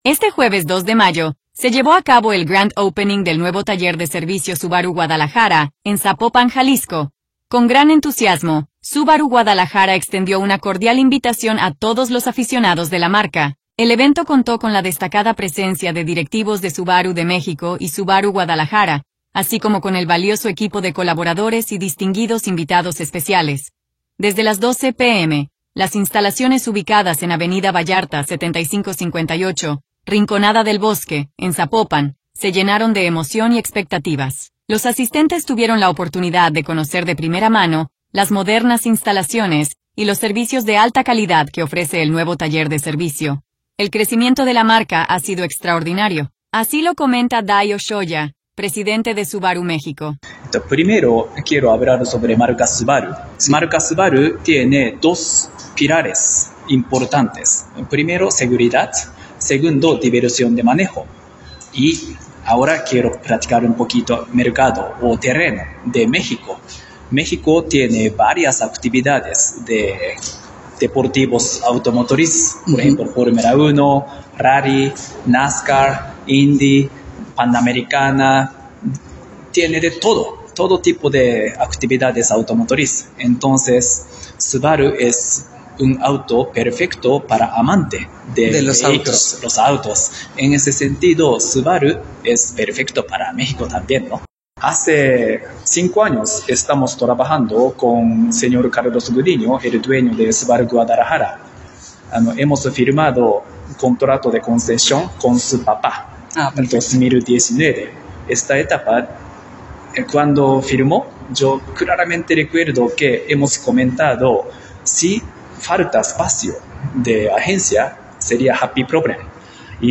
audio Este jueves 2 de mayo, se llevó a cabo el Grand Opening del Nuevo Taller de Servicio Subaru Guadalajara en Zapopan, Jalisco.